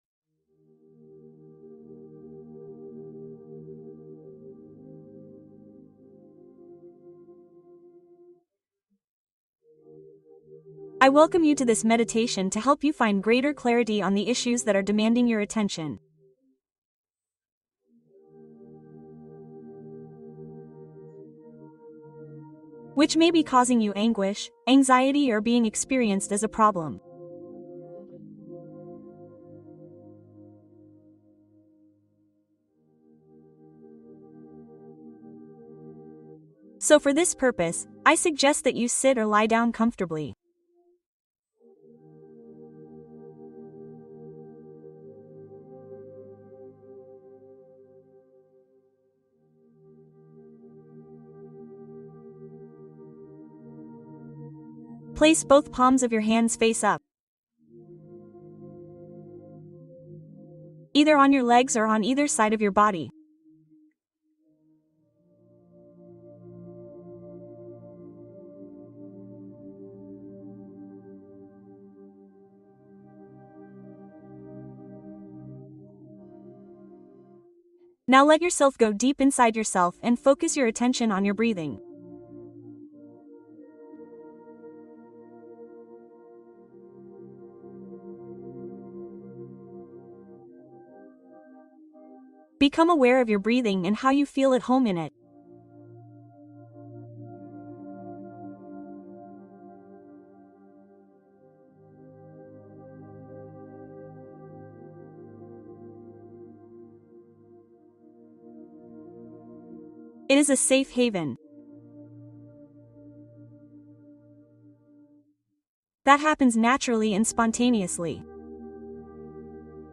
Relato calmante para favorecer un descanso continuo